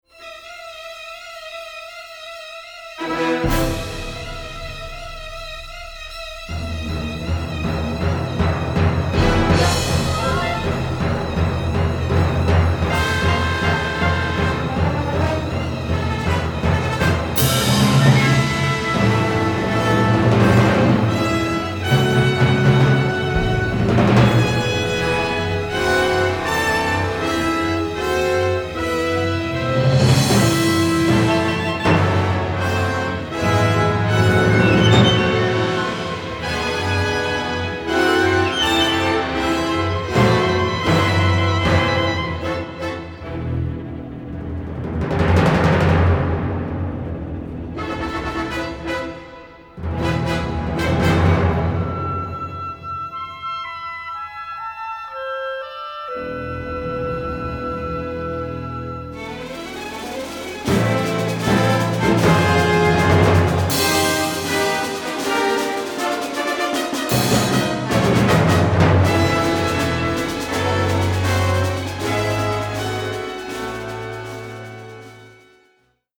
robust, full-blooded music